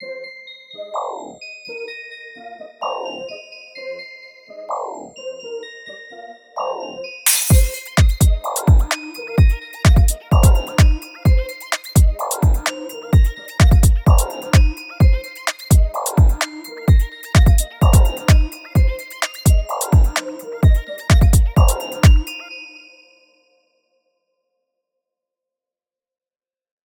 HOUSE TECH
house-tech.wav